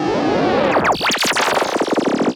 Glitch FX 33.wav